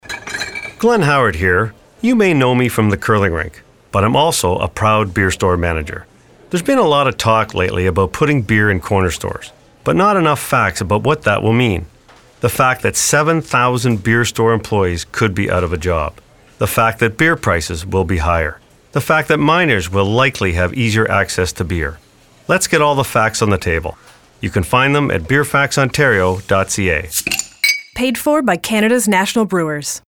There are a total of five 30-second radio ads by Howard and one 30-second TV commercial.
LISTEN: One of the radio ads being used in the campaign (The Beer Store)